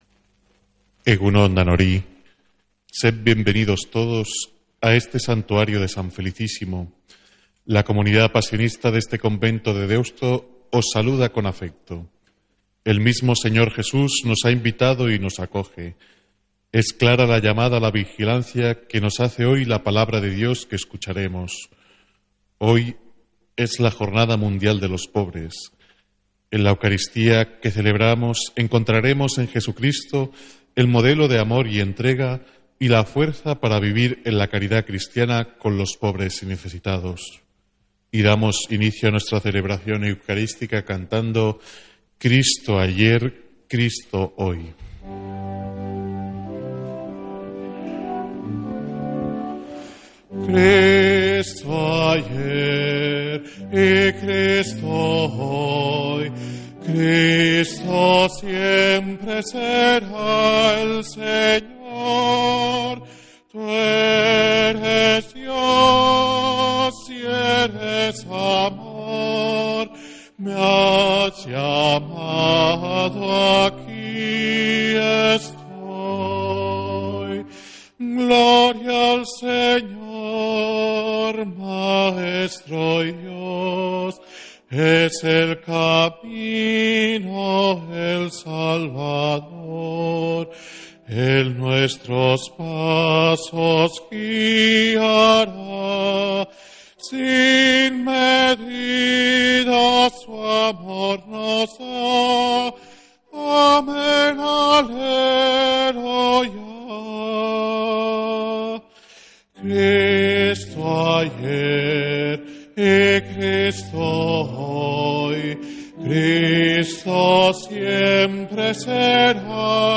Santa Misa desde San Felicísimo en Deusto, domingo 17 de noviembre